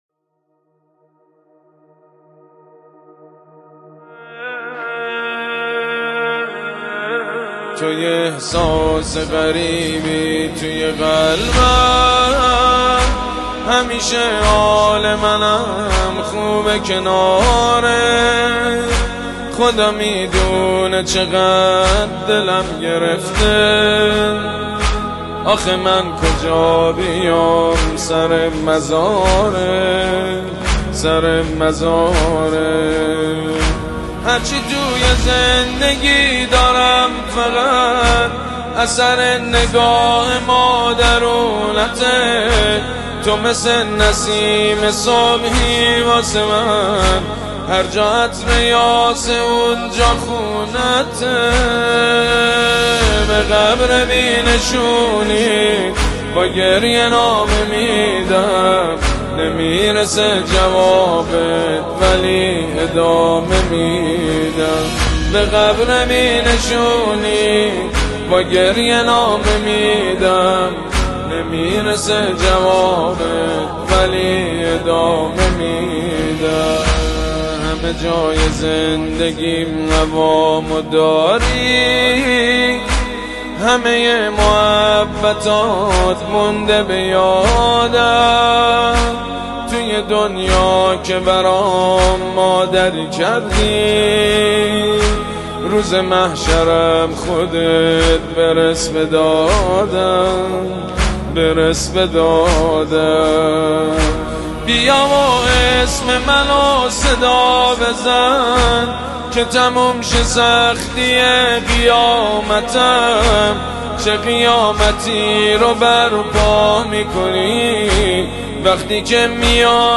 دانلود کلیپ صوتی و تصویری مداحی ویژه ی «ایام فاطمیه» با نوای دلنشین «سید مجید بنی فاطمه» به همراه متن نوحه